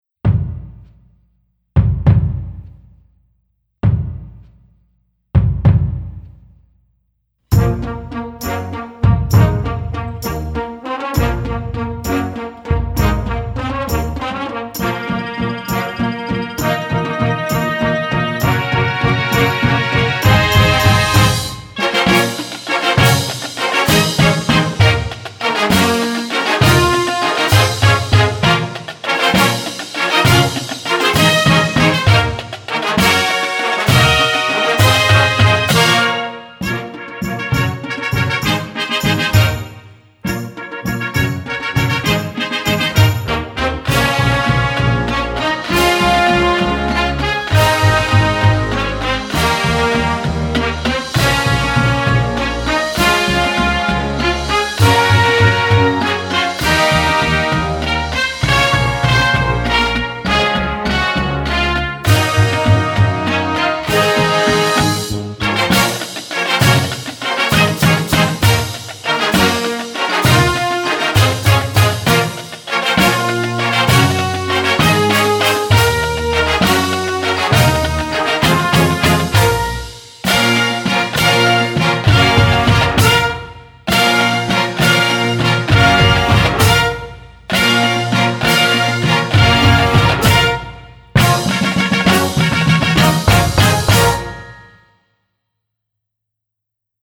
Gattung: Konzertwerk
B-C Besetzung: Blasorchester Tonprobe